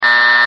wrong_answer.mp3